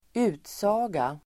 Ladda ner uttalet
utsaga substantiv, statement Uttal: [²'u:tsa:ga]